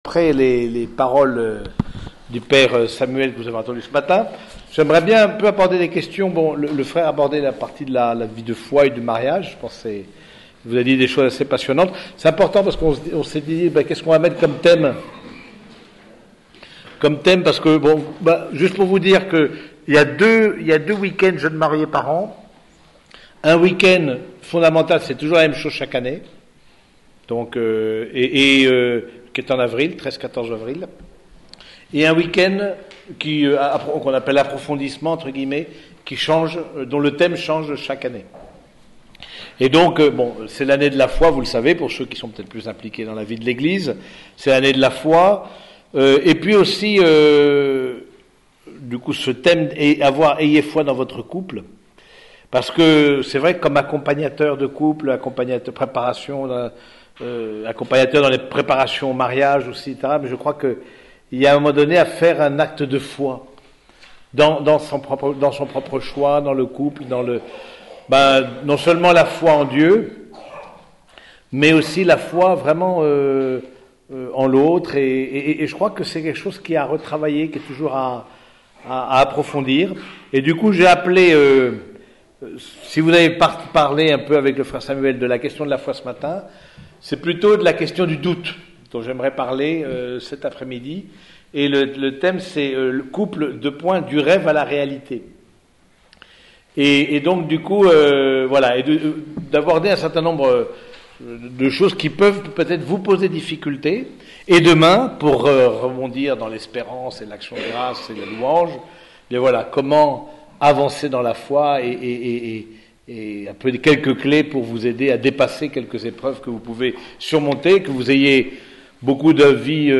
Écouter les enseignements :
Dans cet article, vous pourrez ré-écouter trois enseignements que les frères ont donnés dans le cadre d’un week-end jeunes mariés (2 et 3 février 2013).